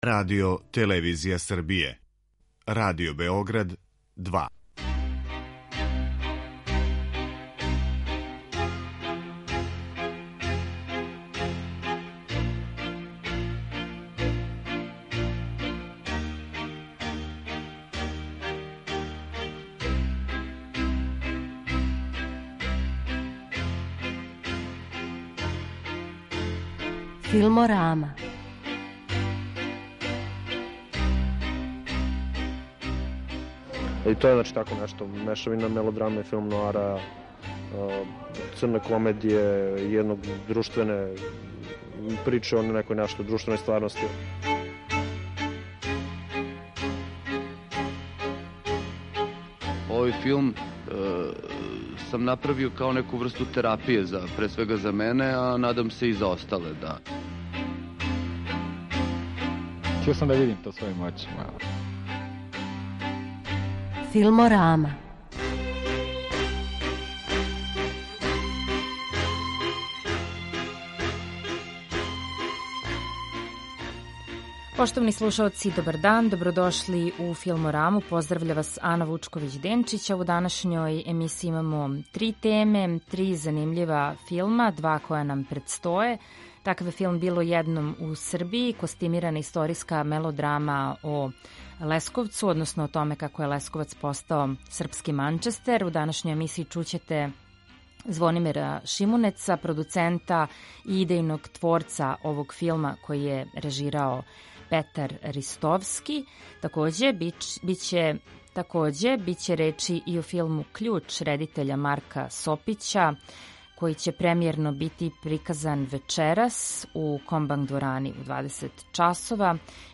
Емисија о седмој уметности